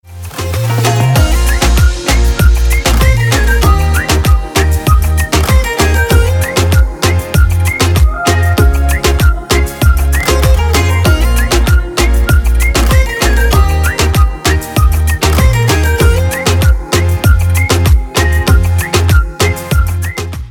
Мелодии без слов на входящий